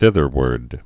(thĭthər-wərd, thĭth-)